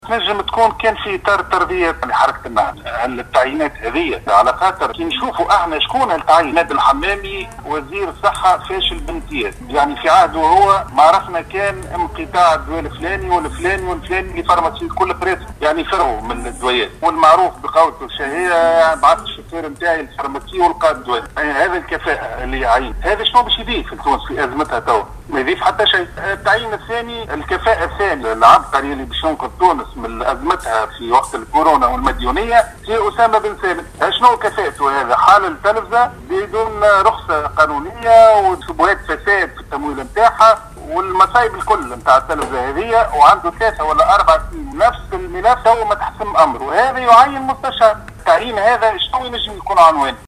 un entretien à Tunisienumérique